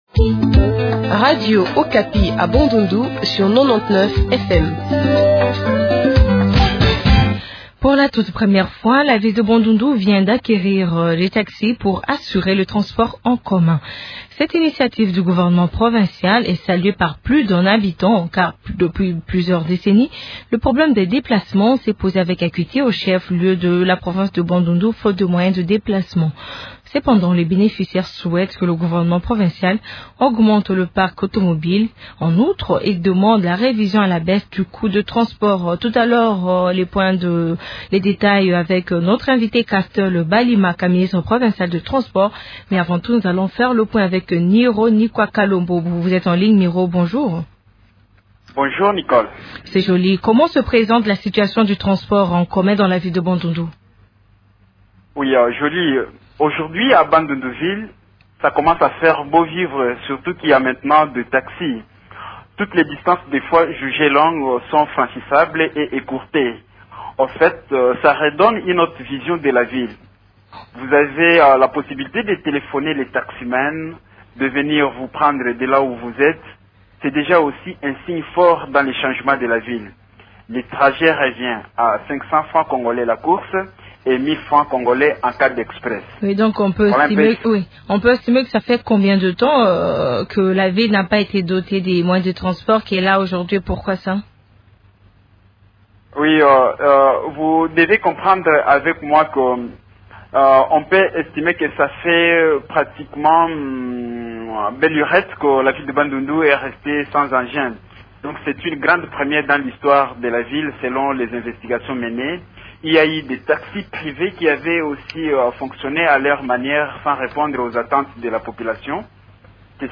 ministre provincial de transport.